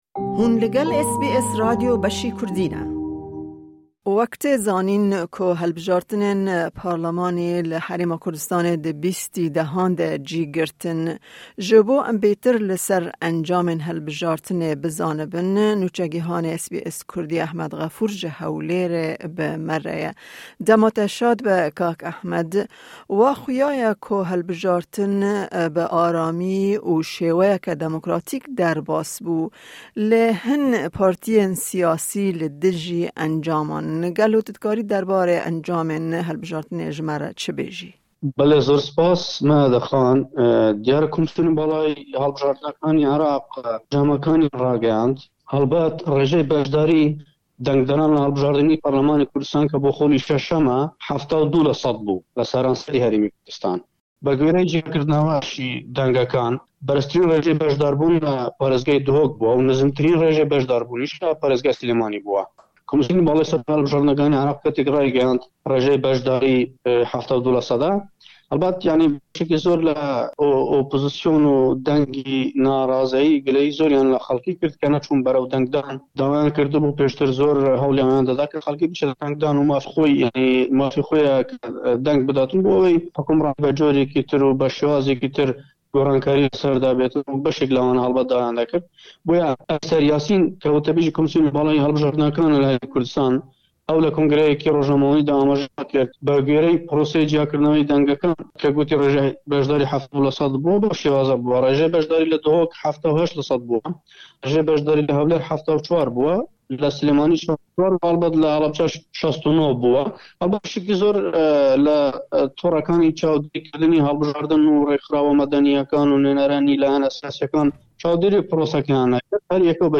Nûçegîhan